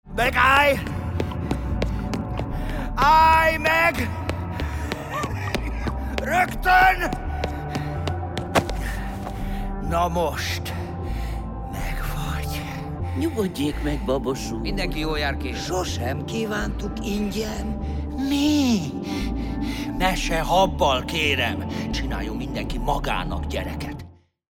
C lépcsőház – hangjáték a Rádiószínpadon